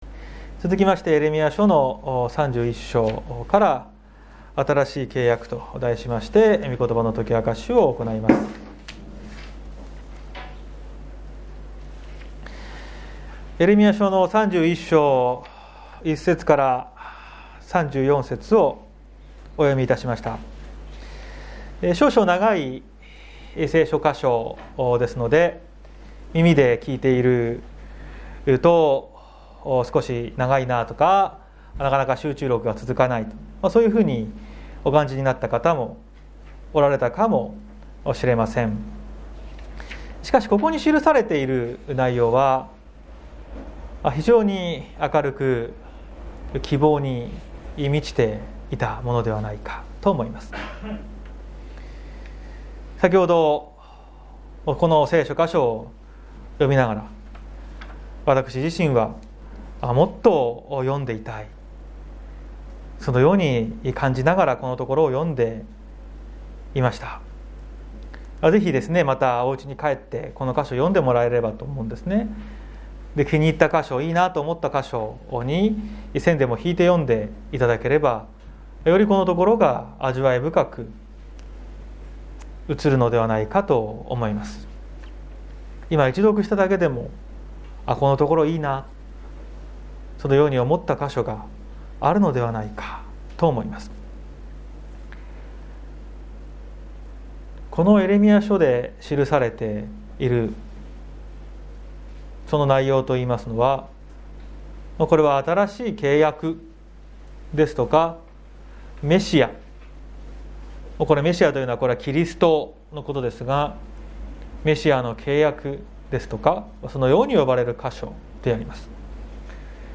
2022年01月23日朝の礼拝「新しい契約」綱島教会
説教アーカイブ。